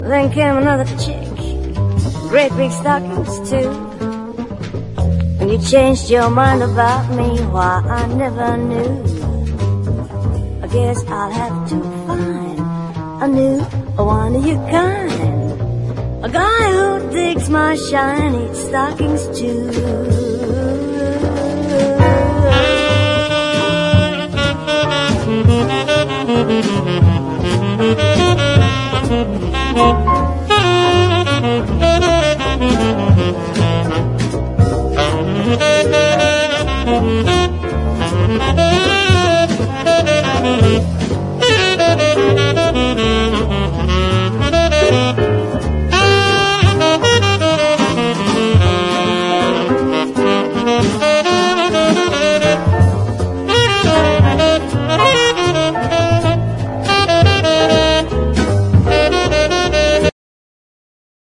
JAZZ / JAZZ VOCAL / EURO JAZZ / CONTEMPORARY JAZZ